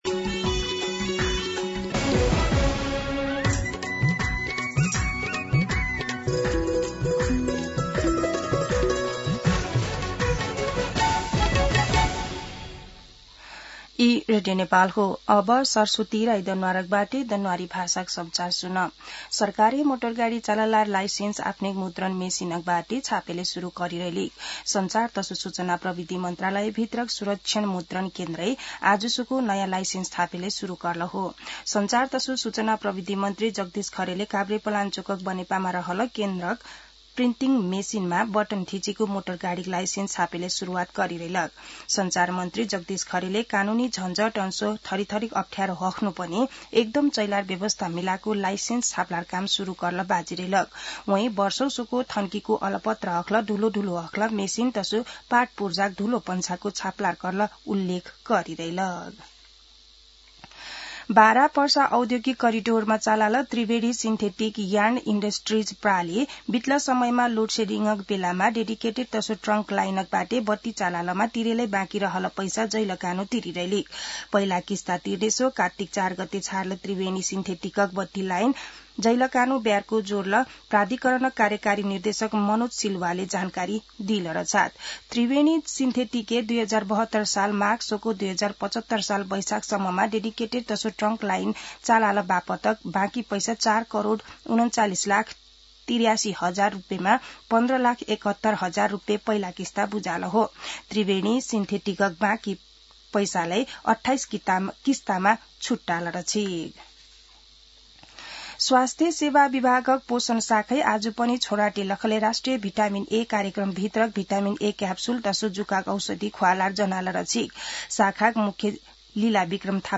दनुवार भाषामा समाचार : २१ कार्तिक , २०८२